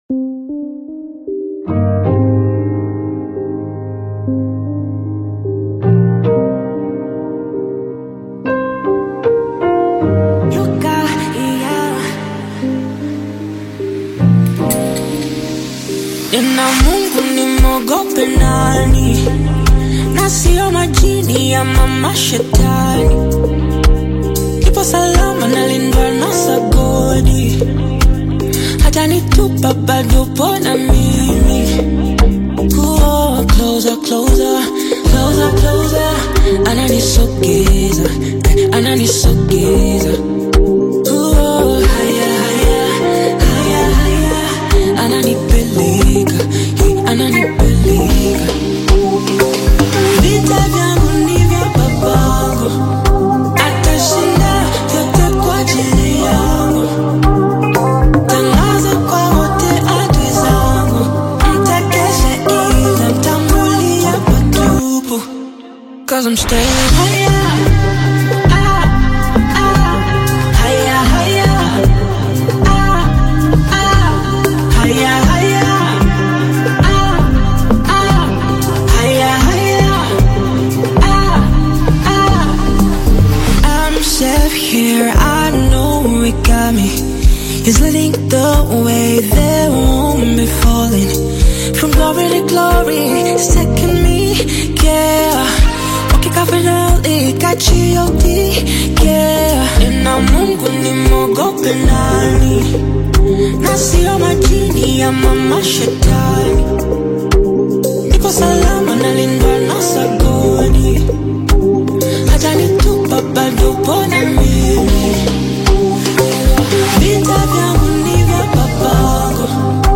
soulful vocals
polished soundscape